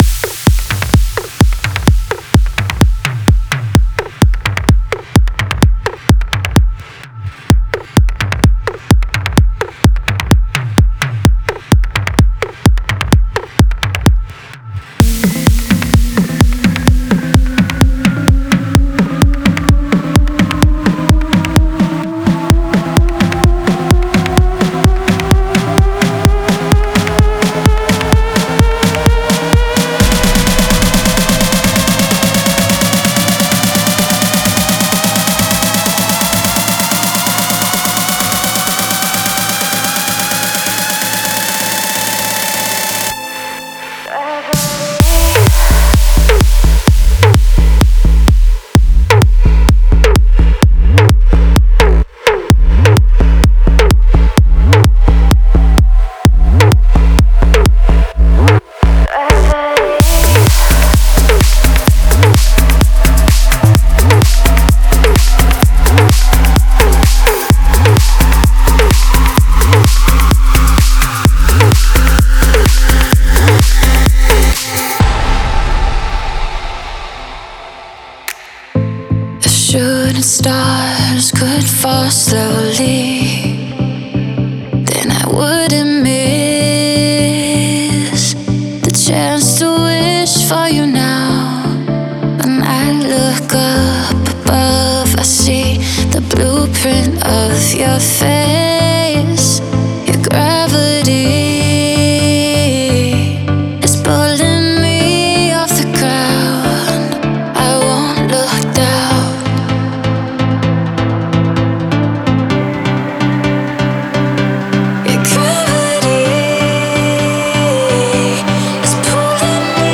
Стиль: Trance / Progressive Trance / Vocal Trance